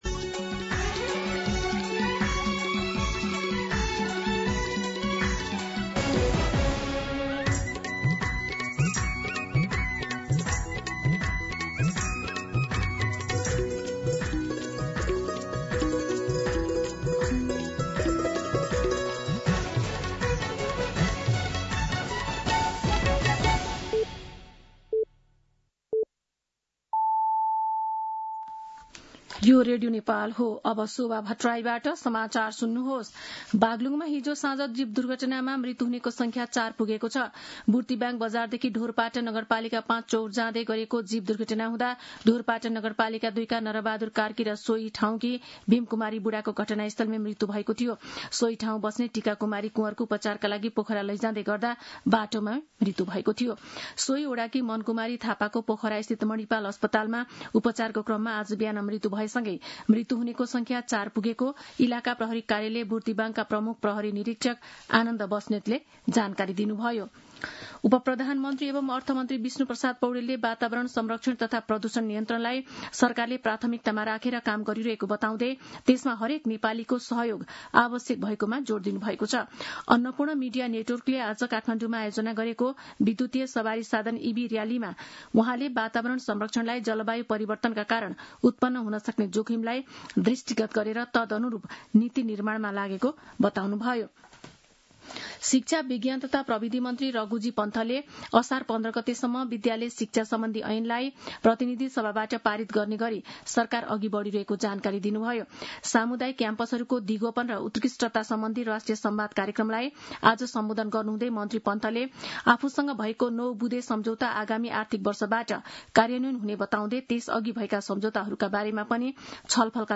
दिउँसो ४ बजेको नेपाली समाचार : ३१ जेठ , २०८२